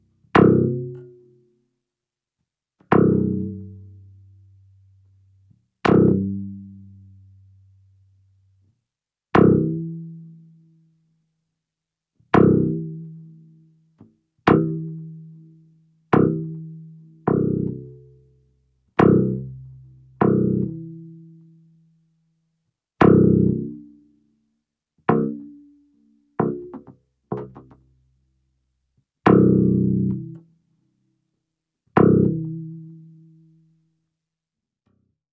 Descarga de Sonidos mp3 Gratis: piano 14.
contactmic_2.mp3